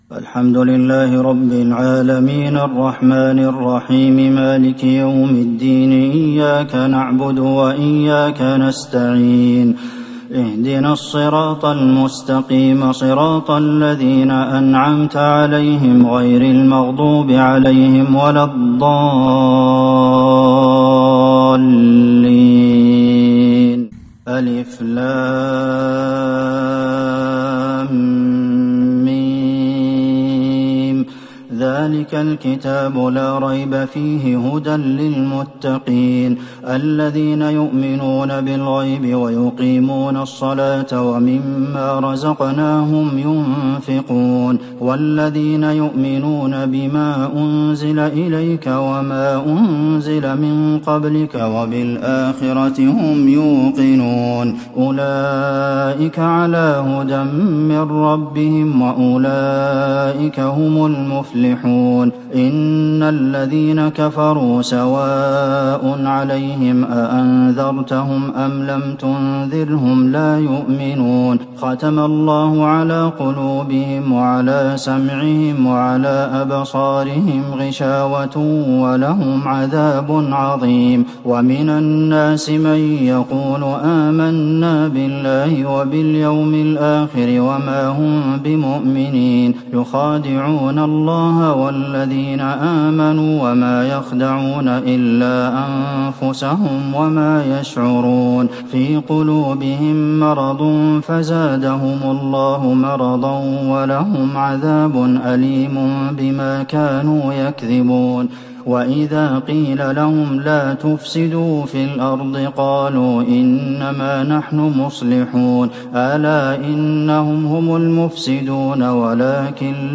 ليلة ١ رمضان ١٤٤١هـ من سورة البقرة {١-٦٦} > تراويح الحرم النبوي عام 1441 🕌 > التراويح - تلاوات الحرمين